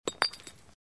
Звуки стакана
Граненый стакан упал на землю и не разбился